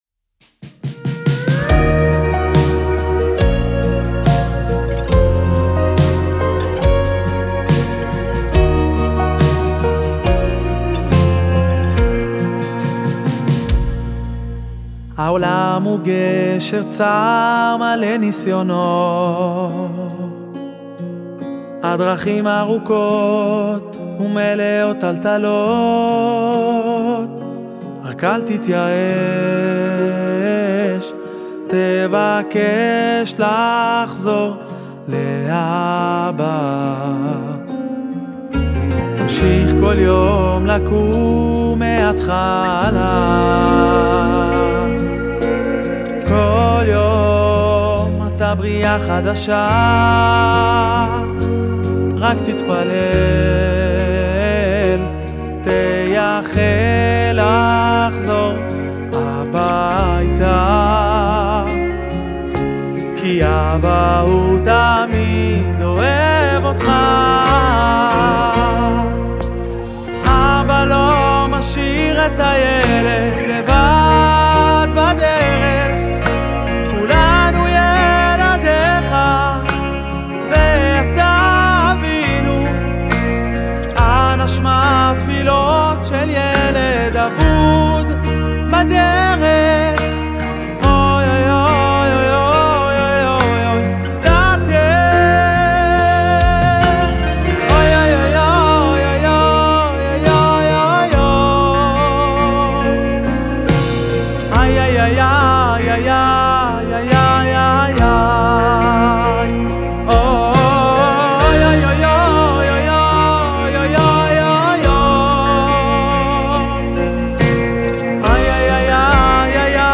קלידים ותכנותים